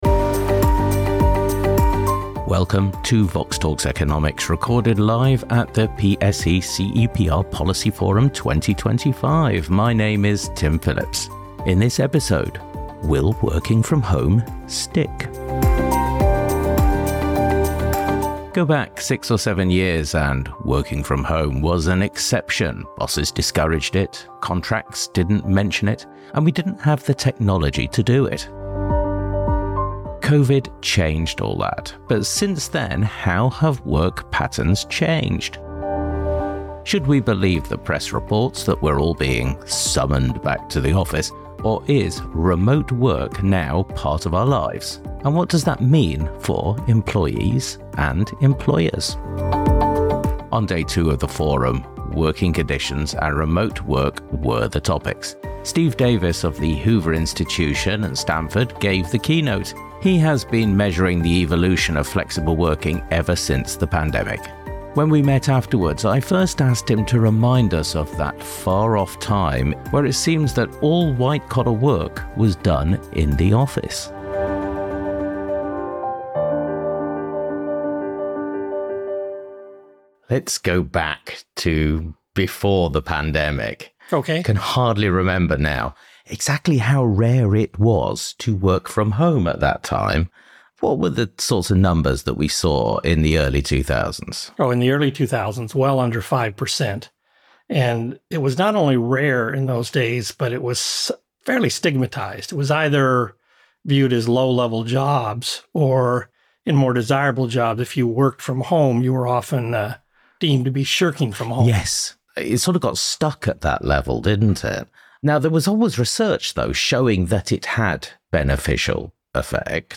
Recorded live at the PSE-CEPR Policy Forum 2025.